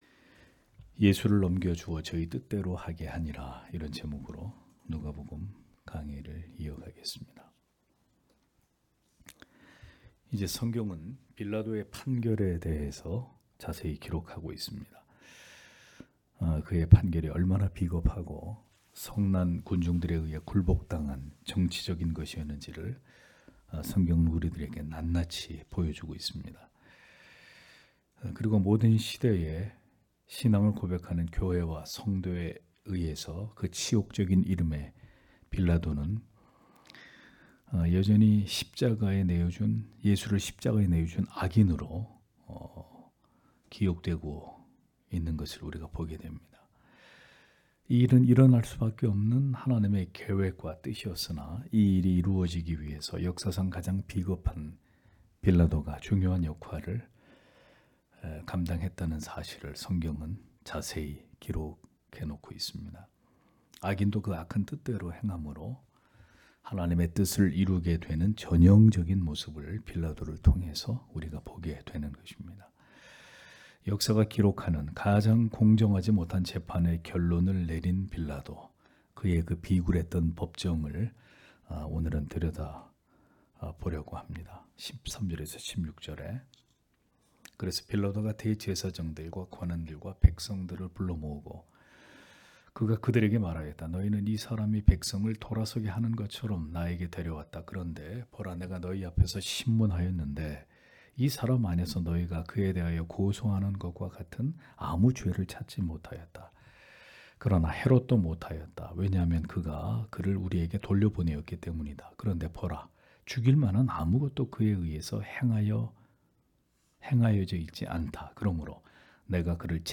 금요기도회 - [누가복음 강해 176] '예수를 넘겨주어 저희 뜻대로 하게 하니라' (눅 23장 13- 25절)